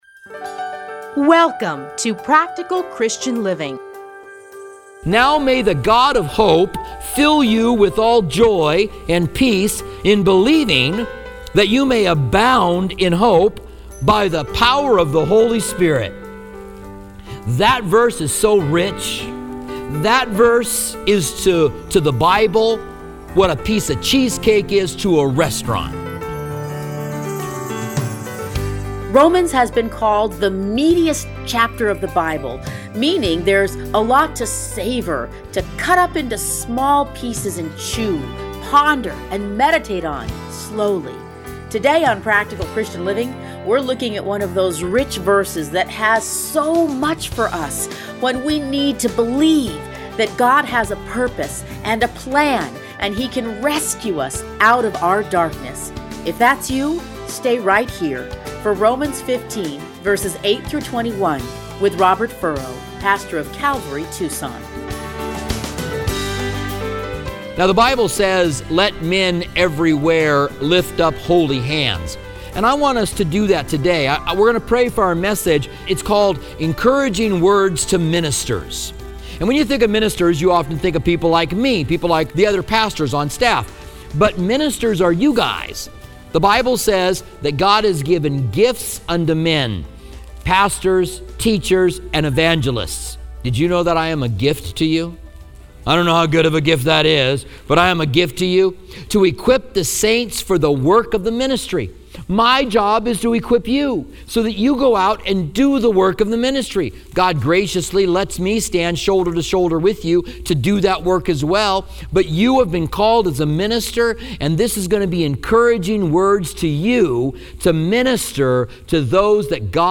Listen here to his commentary on Romans.